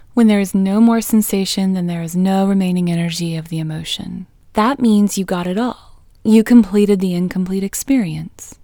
IN – the Second Way – English Female 26